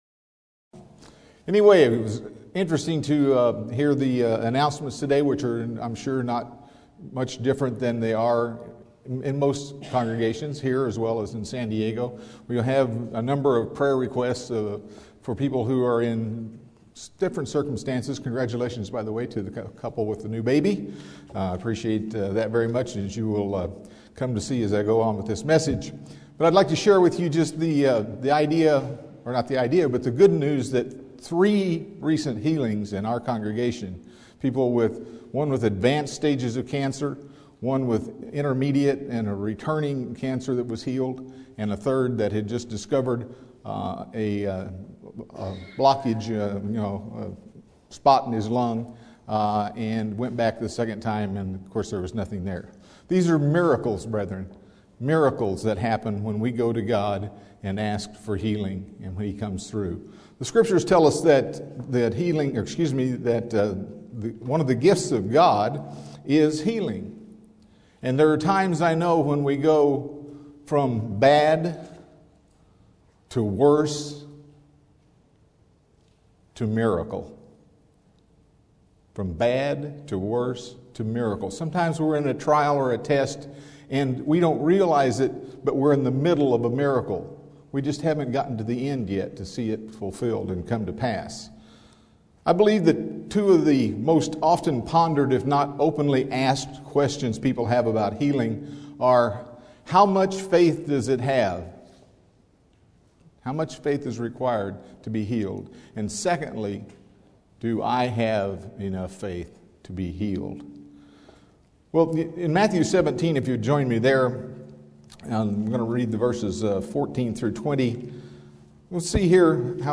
This sermon examines Biblical principles of faith and healing.